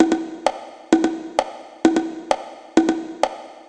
130_bongo_1.wav